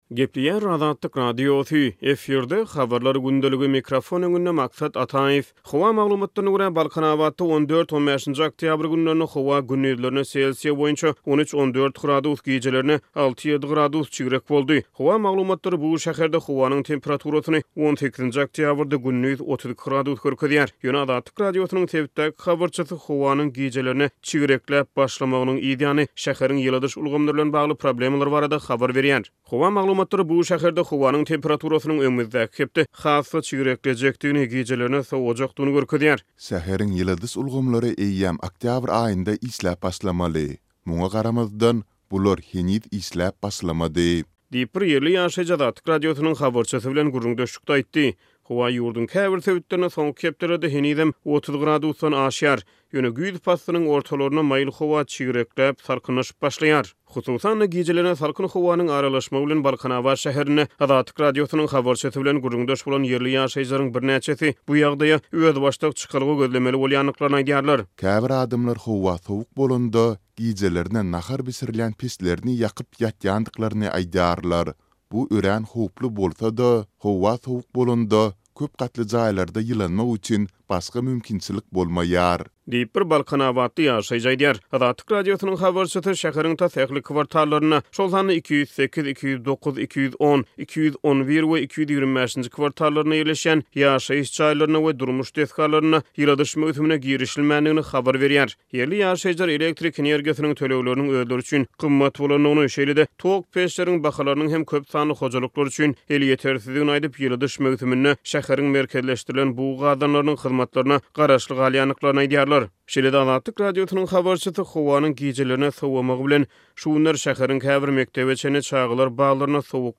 Howa maglumatlaryna görä, Balkanabatda 14-15-nji oktýabr günlerinde howa gündizlerine selsiýa boýunça 13, 14 gradus gijelerine 6, 7 gradus çigrek boldy. Habarçymyz howanyň gijelerine çigrekläp başlamagynyň yz ýany, şäheriň ýyladyş ulgamlary bilen bagly problemalar barada habar berýär.